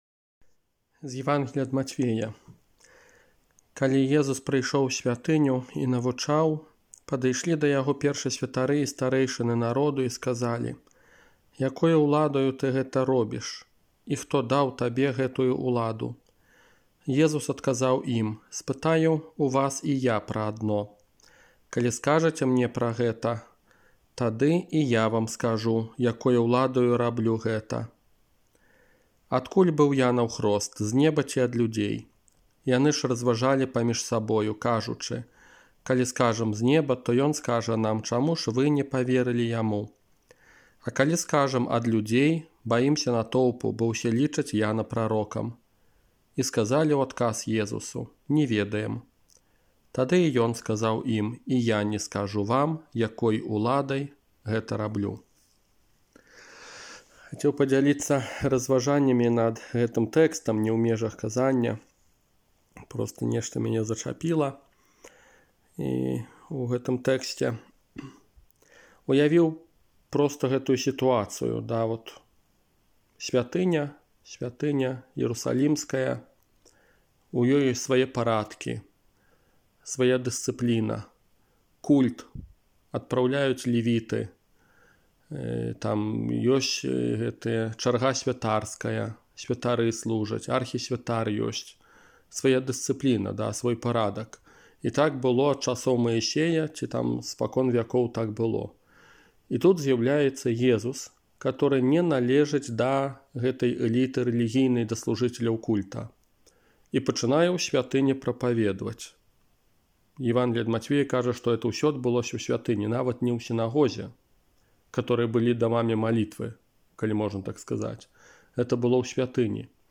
ОРША - ПАРАФІЯ СВЯТОГА ЯЗЭПА
Iзноу_пра_богашуканне_канферэнцыя.m4a